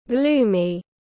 Trời ảm đạm tiếng anh gọi là gloomy, phiên âm tiếng anh đọc là /ˈɡluː.mi/.
Gloomy /ˈɡluː.mi/
Gloomy.mp3